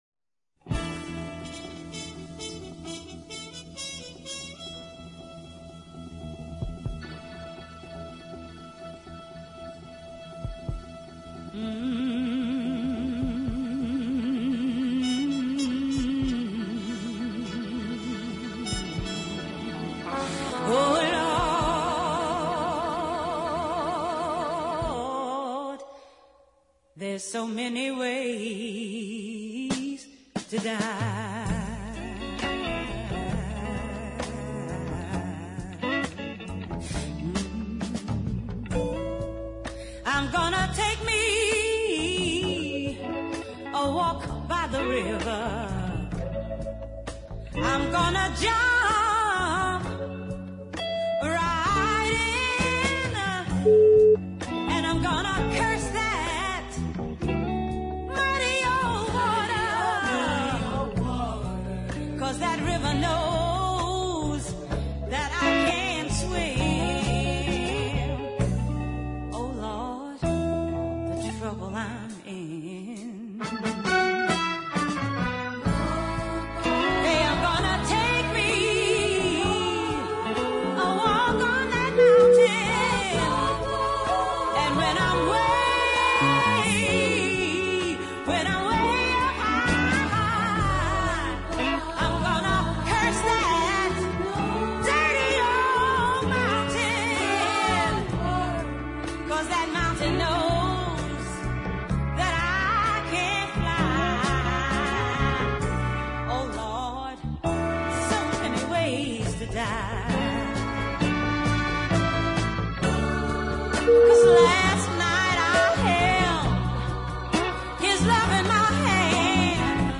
New York soul gospel
guitar